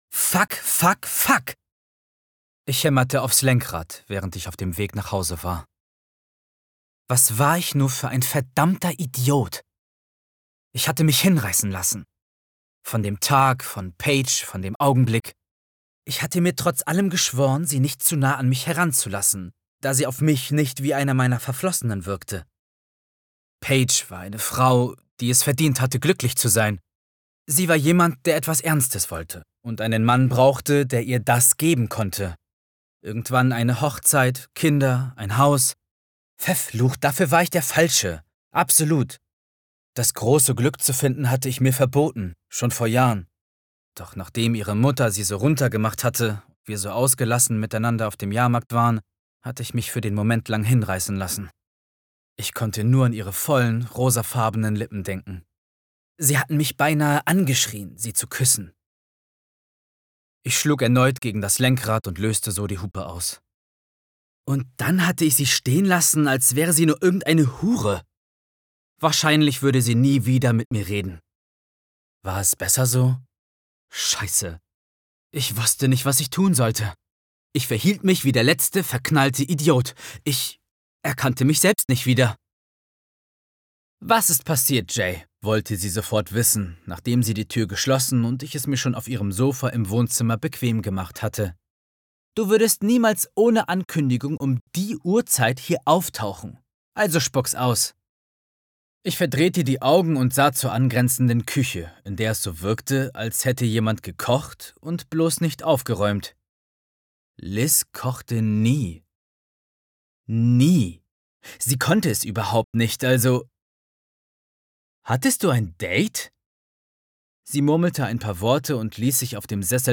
Reue über einen Kuss, innere Distanz und unausgesprochene Konsequenzen. Hörbuch, Romance